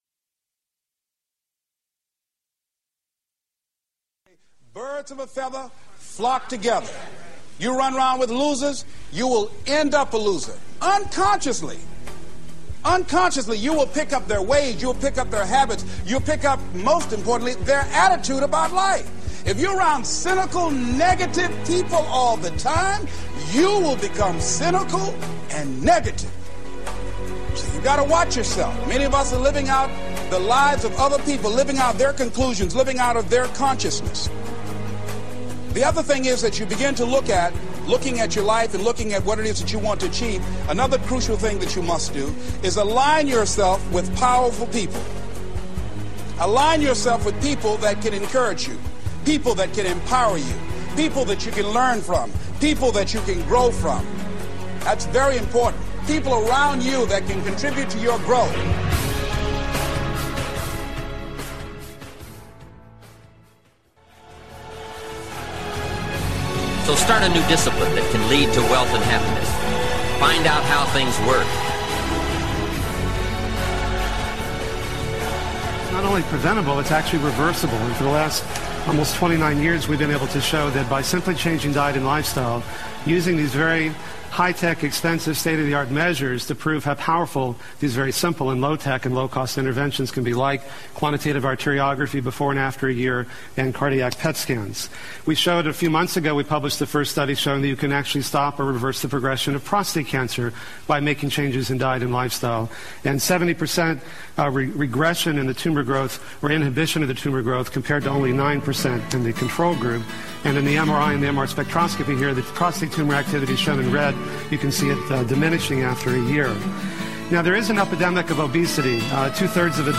Between songs you will often hear military “jodies” which are used to maintain rhythm (aka jogging cadences) and add some MOTIVATION to exercise or to GET THINGS DONE NOW!